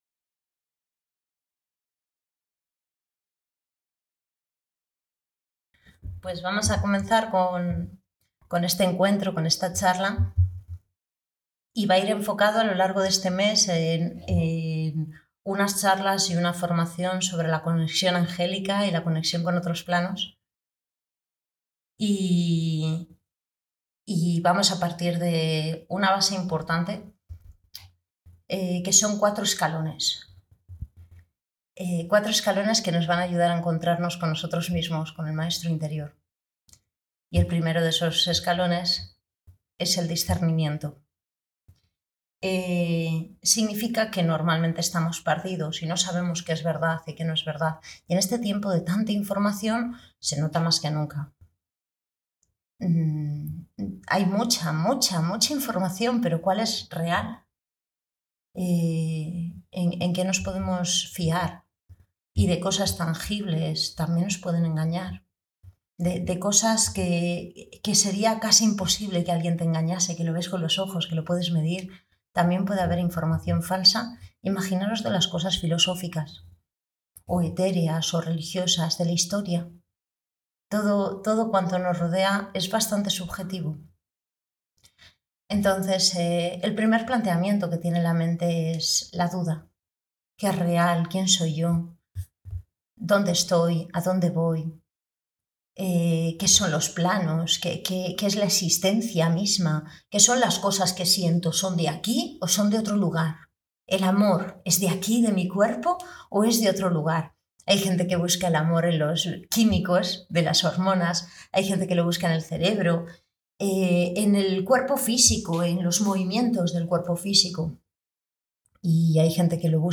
Charla para el grupo de trabajo